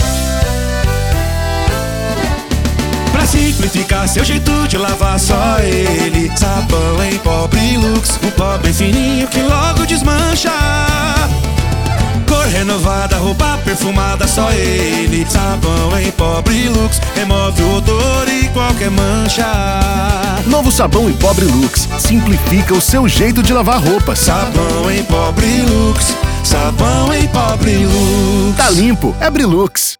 Produtora Trilha Sonora